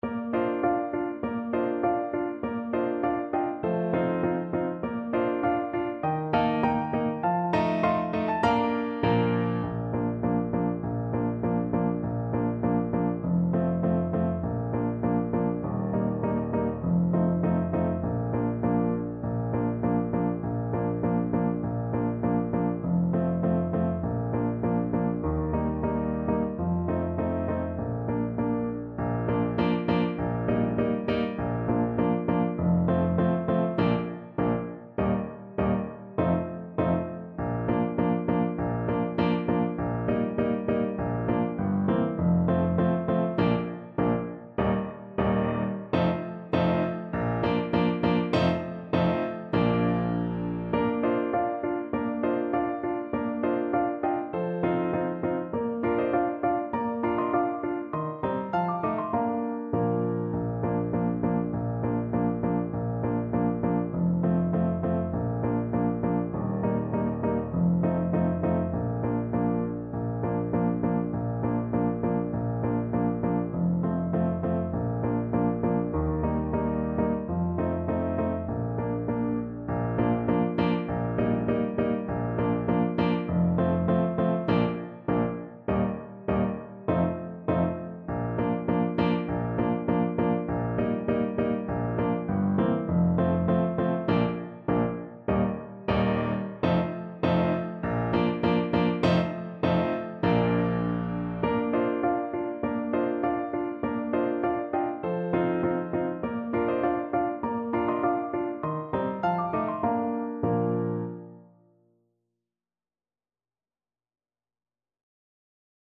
Play (or use space bar on your keyboard) Pause Music Playalong - Piano Accompaniment Playalong Band Accompaniment not yet available transpose reset tempo print settings full screen
Alto Saxophone
Bb major (Sounding Pitch) G major (Alto Saxophone in Eb) (View more Bb major Music for Saxophone )
Moderato
4/4 (View more 4/4 Music)
Pop (View more Pop Saxophone Music)